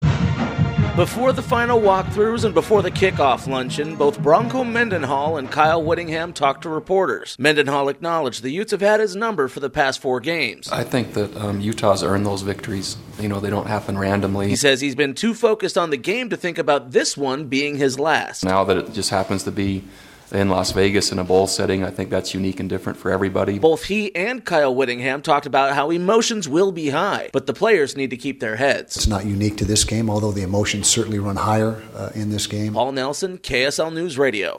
BYU Head Coach Bronco Mendenhall and University of Utah Head Coach Kyle Wittingham held their final press conference before tomorrow's Royal Purple's Las Vegas Bowl.